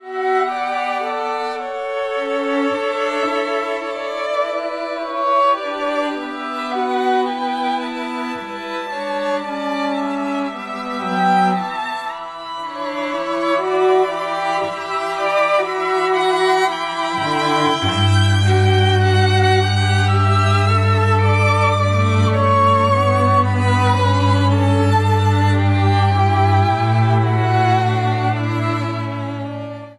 für Streichquartett
Beschreibung:Kammermusik; Klassik
Besetzung:Streichquartett